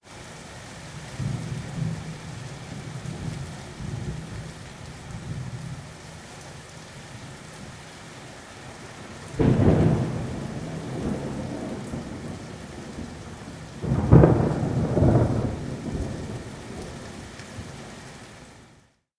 WEATHE R现场录音 " 00006 grzmot 6
描述：有雨的小风暴，高出路面50厘米，单声道，由AKG c414 TLII
标签： 闪电 风暴